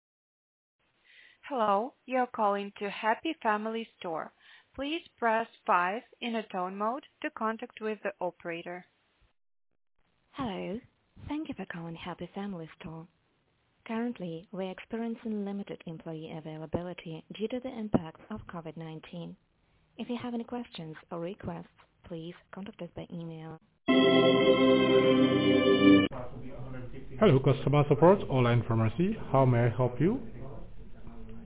This bozo sounds very professional. :joy: